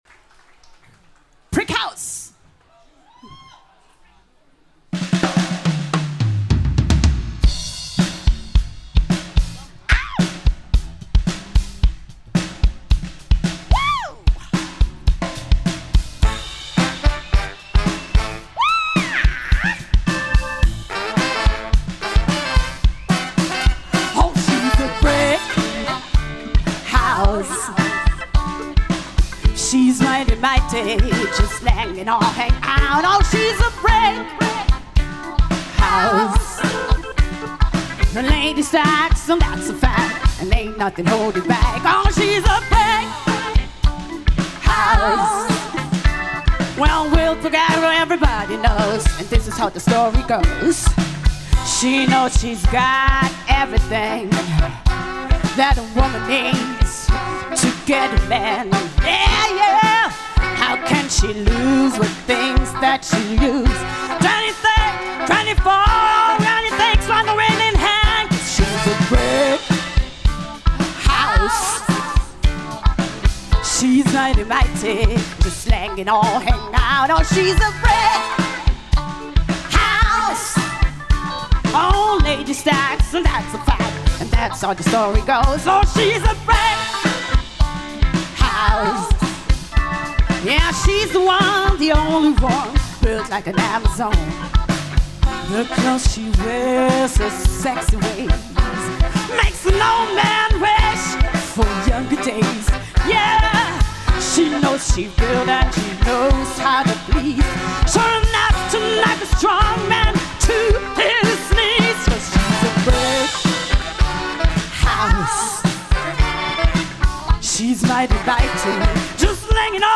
· Genre (Stil): Soul
· Kanal-Modus: stereo · Kommentar